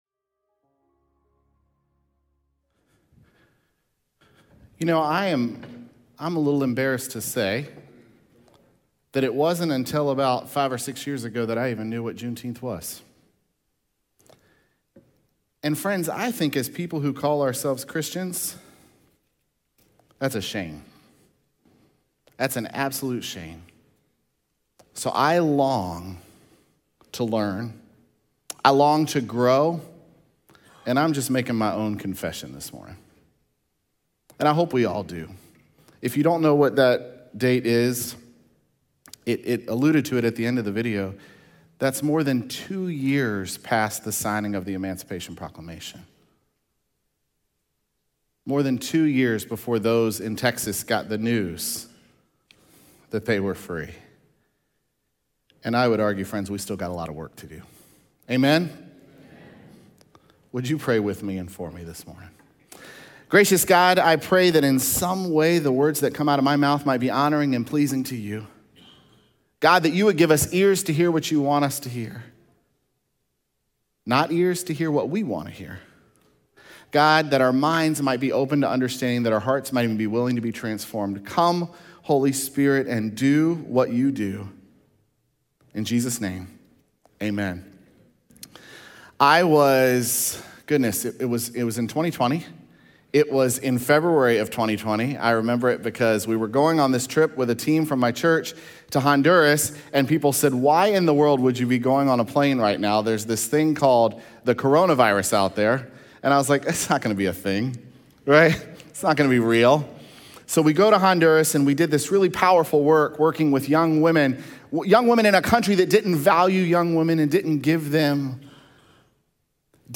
June25Sermon.mp3